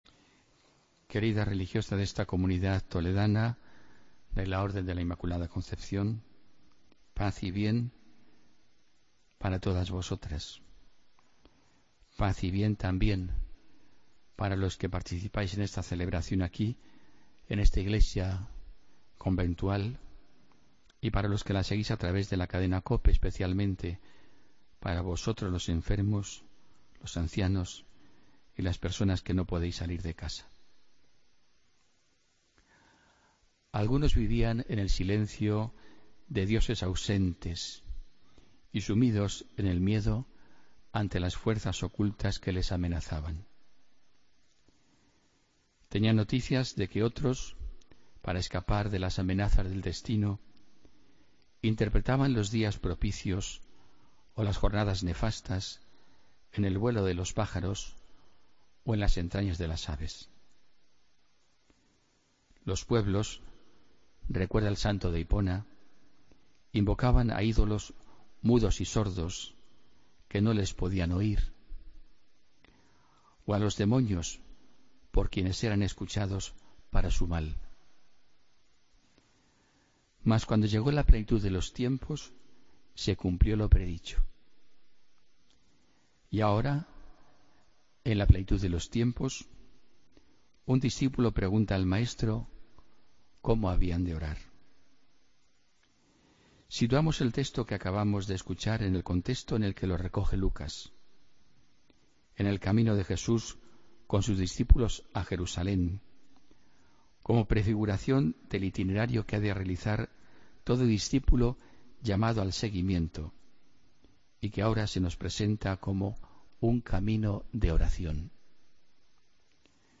AUDIO: Homilía del domingo 24 de julio de 2016